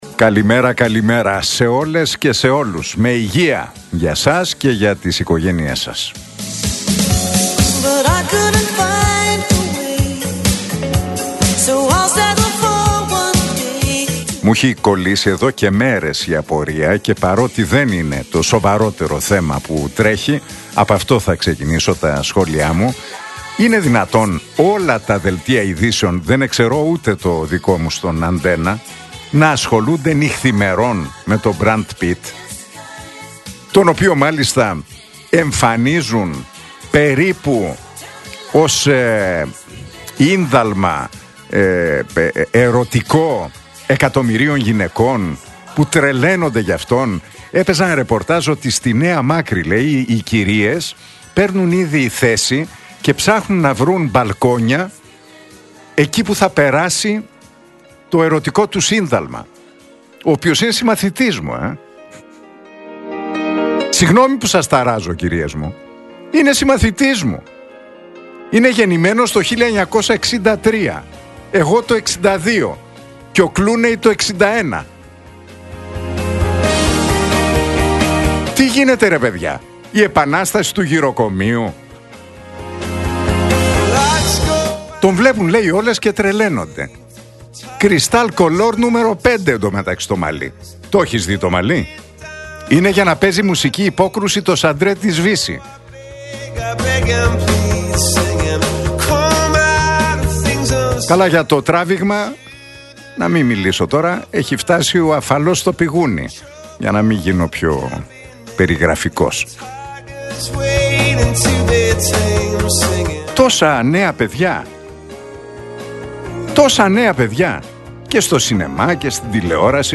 Ακούστε το σχόλιο του Νίκου Χατζηνικολάου στον ραδιοφωνικό σταθμό Realfm 97,8, την Τετάρτη 25 Φεβρουαρίου 2026.